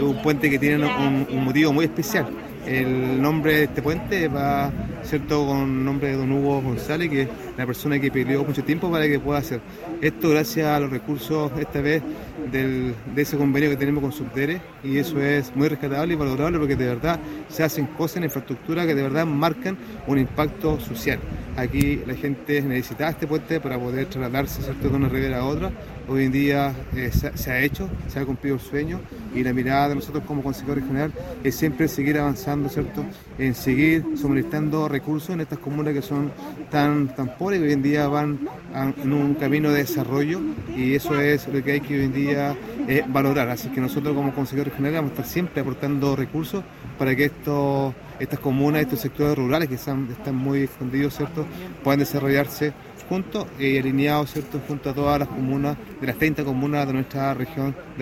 El presidente de la Comisión Provincial Osorno, Francisco Paredes, expresó que es un logro significativo, especialmente para quienes viven en aislamiento.